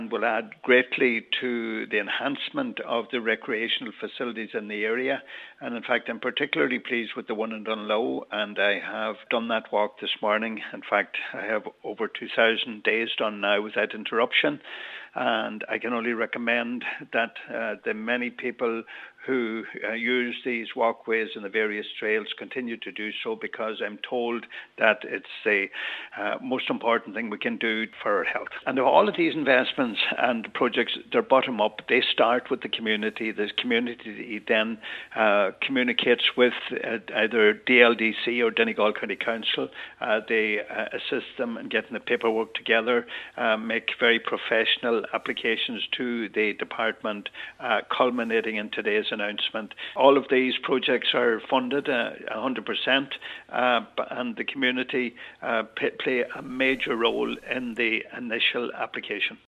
Deputy Pat the Cope Gallagher says it’s a significant announcement………